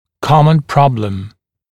[‘kɔmən ‘prɔbləm][‘комэн ‘проблэм]распространенная проблема, наиболее частая проблема